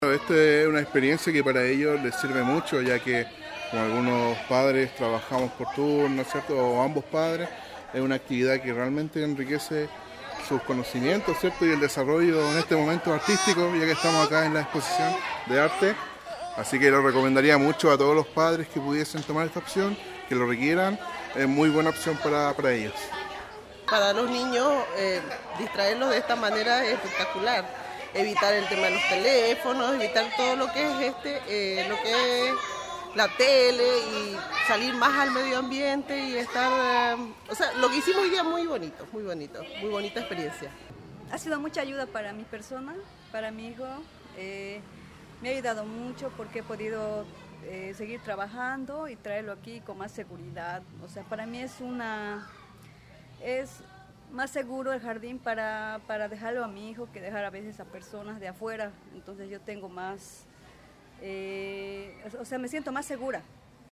La iniciativa aún es agradecida por los apoderados y familias beneficiadas: